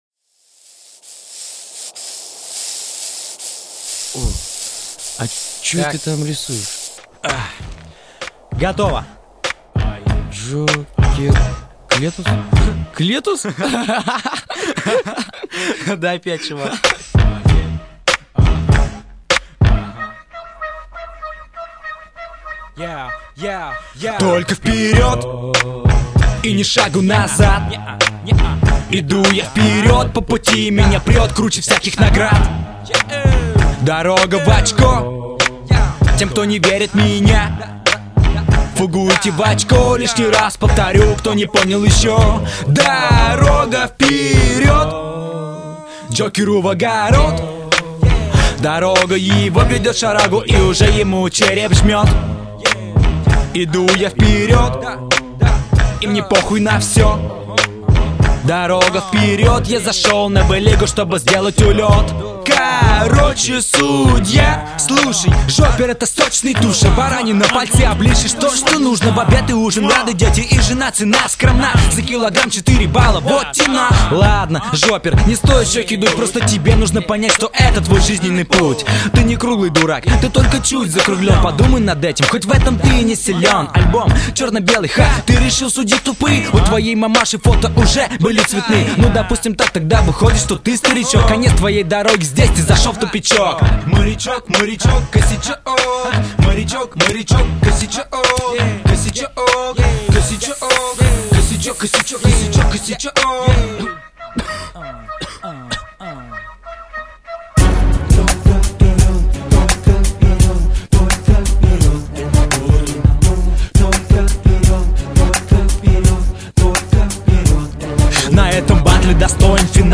• Баттлы:, 2004-05 Рэп Комментарии